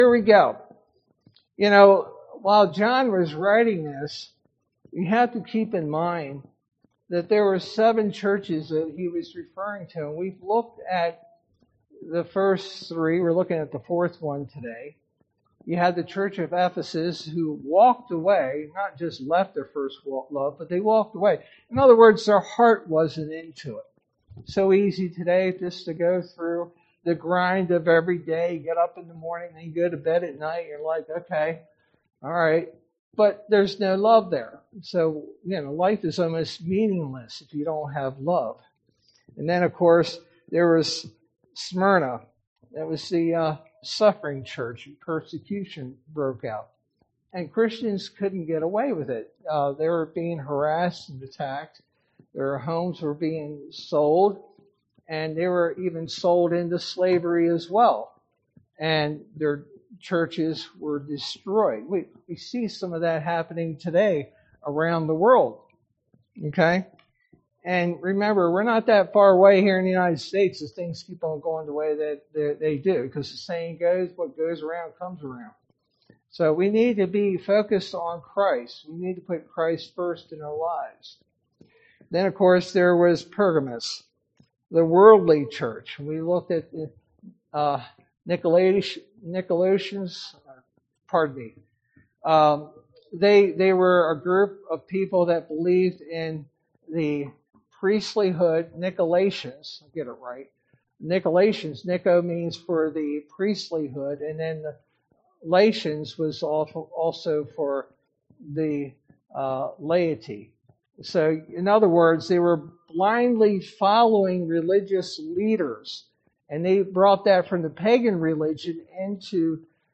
Sermon verse: Revelation 2:18-29